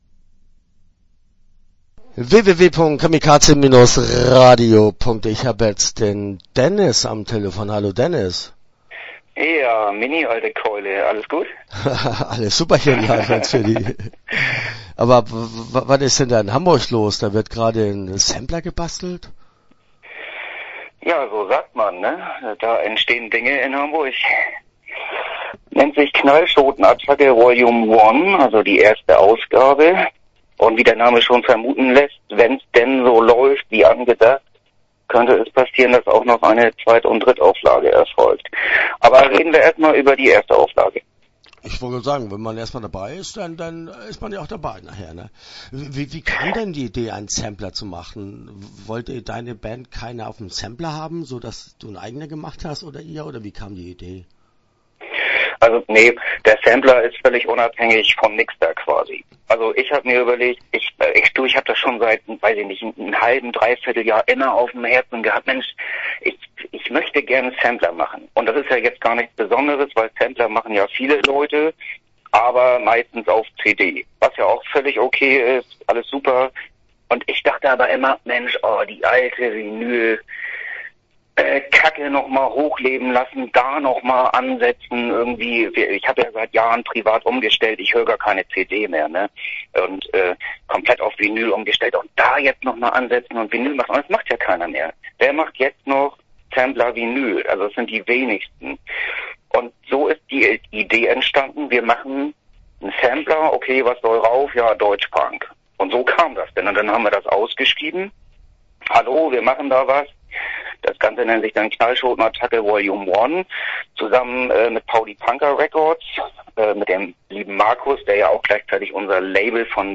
Knallschoten Attacke - Interview Teil 1 (12:38)